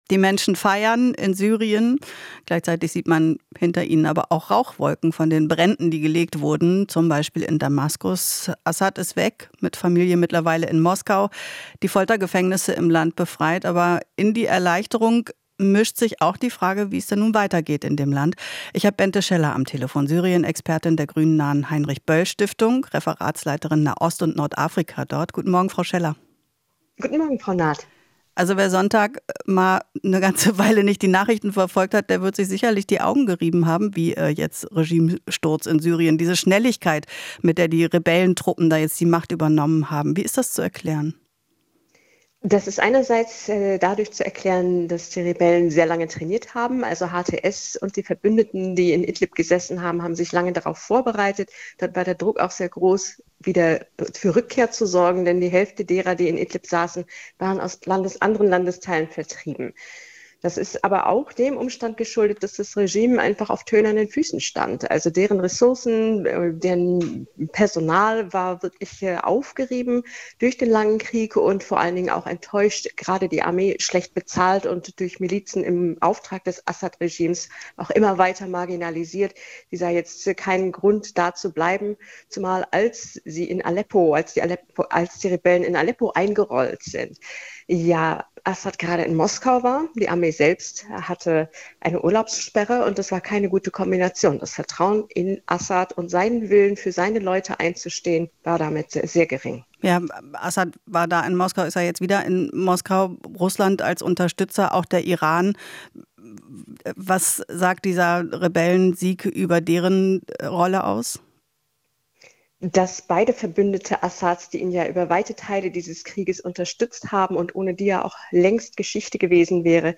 Interview - Syrien-Expertin: Syrer zwischen Hoffnung und Zukunftsangst